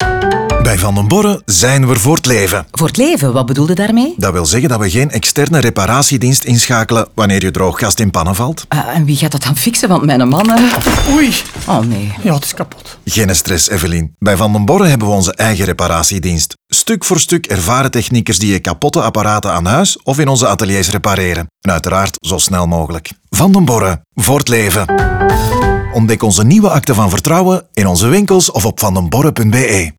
Zo gaat de voice-over telkens in dialoog met verschillende klanten en helpt hij hen met al hun vragen.
Last but not least kreeg ook het soundlogo een lichte make-over.